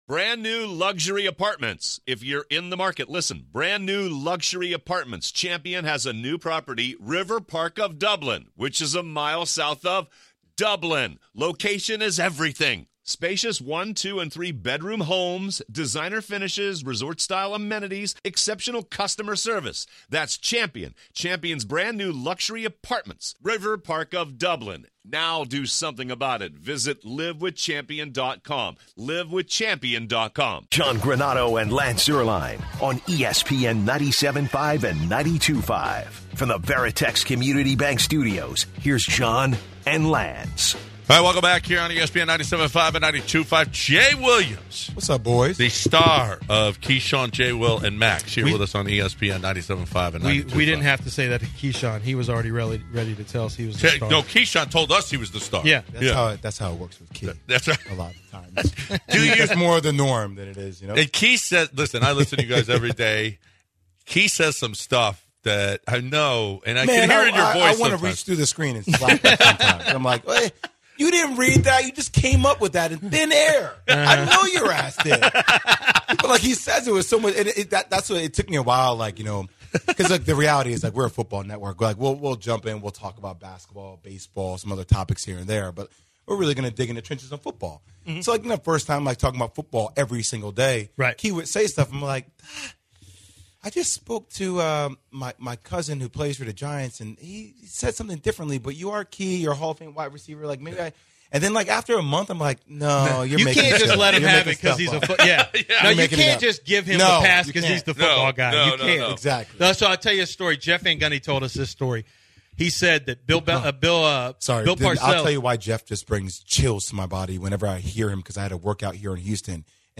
Special Guest Co Host of show NBA pregame show NBA Countdown Jay Williams joins the Bench